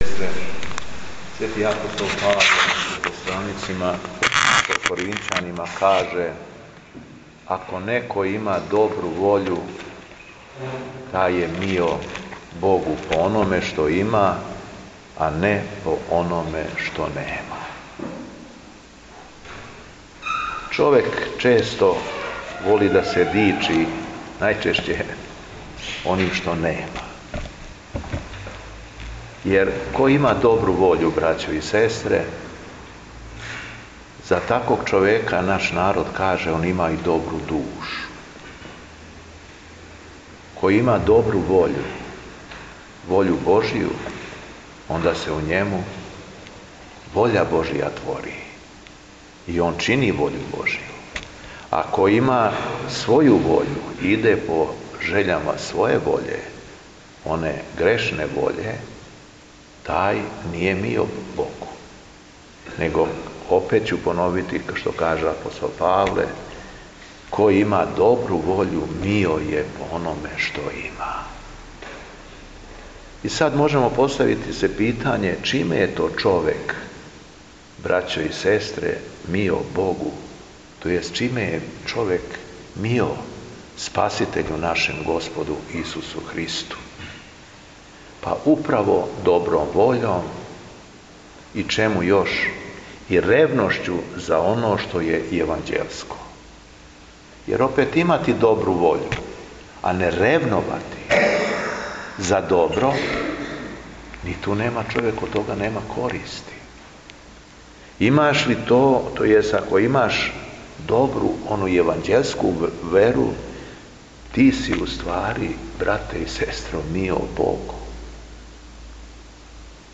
Беседа Његовог Високопреосвештенства Митрополита шумадијског г. Јована
Након прочитаног јеванђељске перикопе верном народу се надахнутим беседом обратио Митрополит Јован: